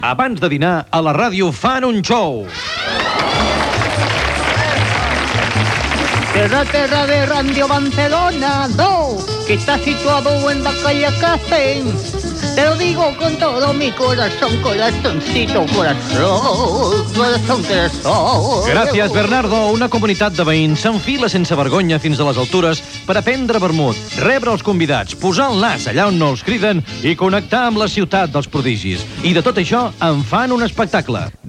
Presentació del programa
Entreteniment